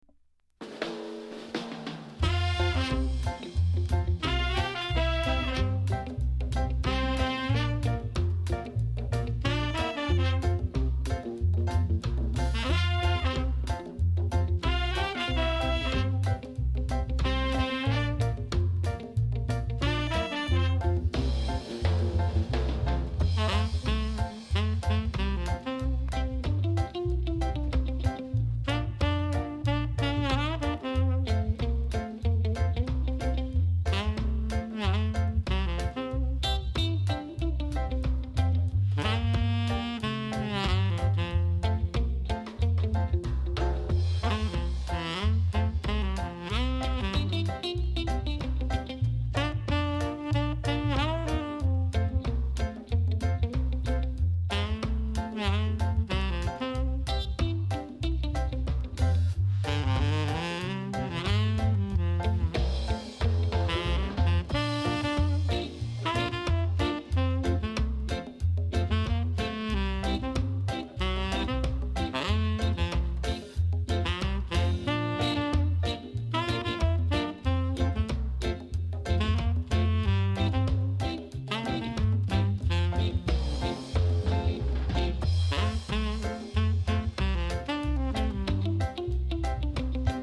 第1弾は、通算5枚目となるオリジナル・ロックステディとカヴァー・スカをカップリング。